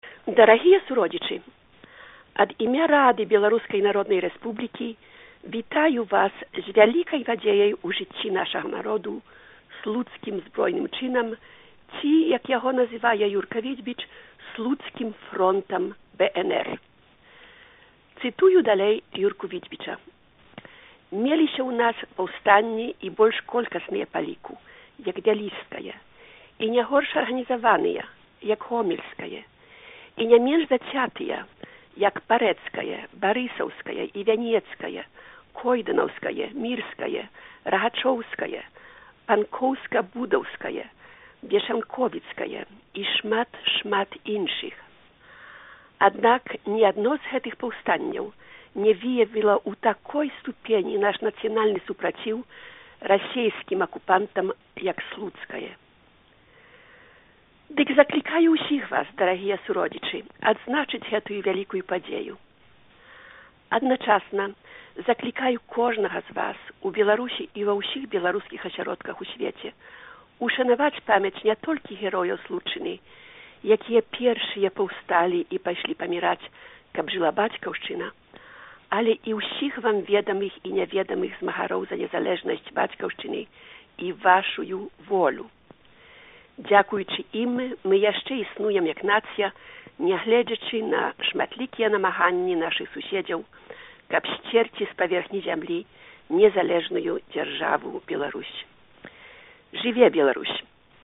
З нагоды ўгодкаў Слуцкага Збройнага Чыну Старшыня Рады БНР Івонка Сурвіла зьвярнулася да беларусаў.
Зварот Івонкі Сурвілы